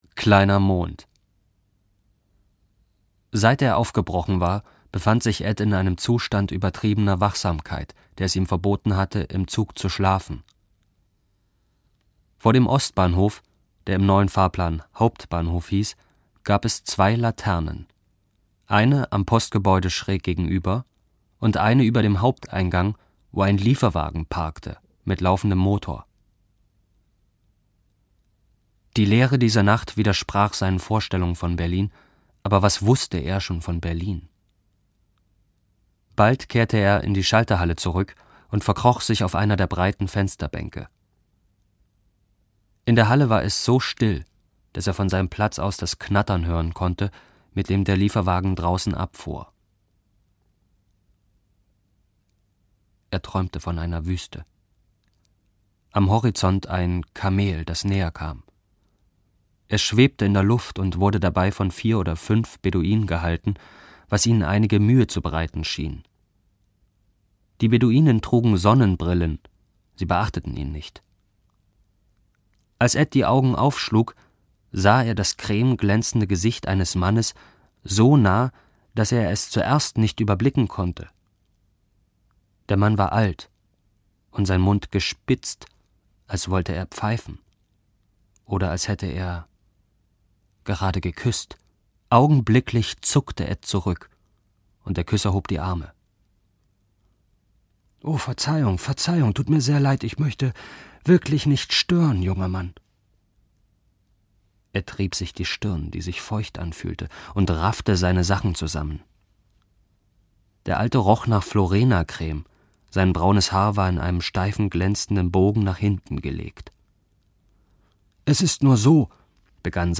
Kruso - Lutz Seiler - Hörbuch